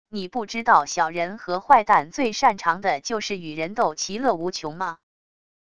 你不知道小人和坏蛋最擅长的就是与人斗其乐无穷吗wav音频生成系统WAV Audio Player